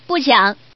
Woman_NoRob.mp3